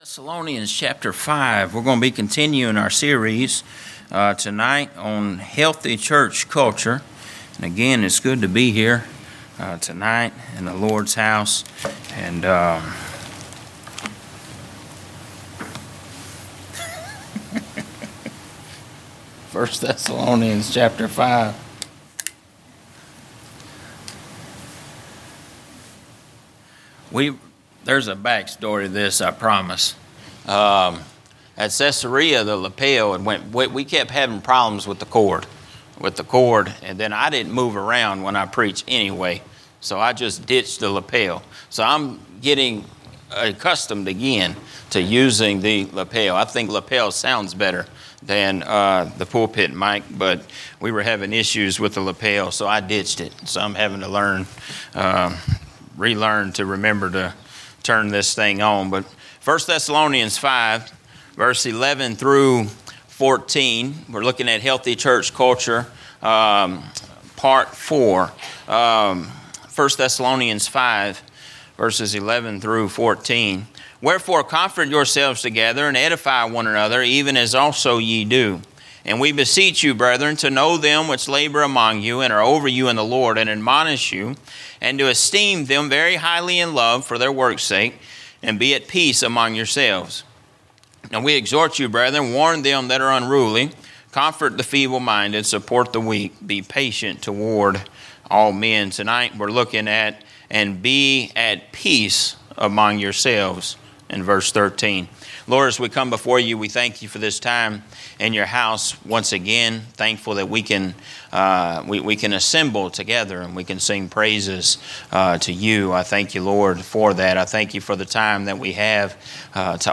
A message from the series "General Preaching." A look at the six trials of Jesus leading up to His crucifixion